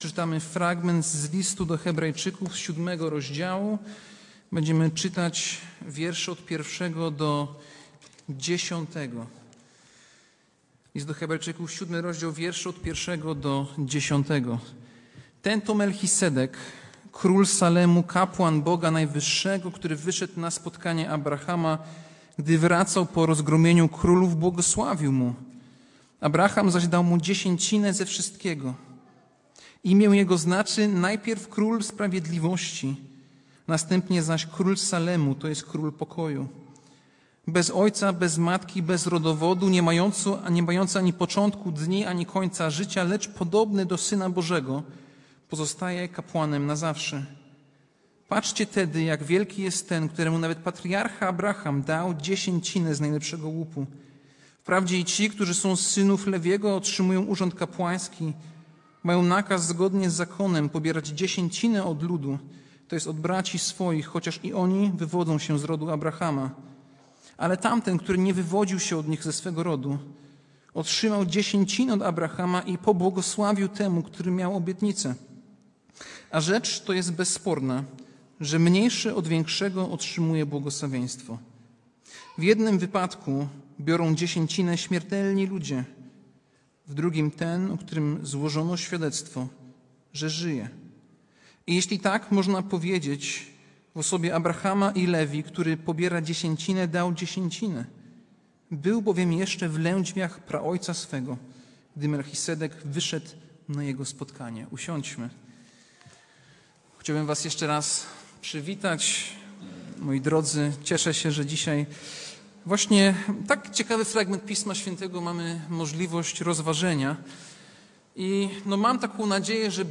Passage: List do Hebrajczyków 7, 1-10 Kazanie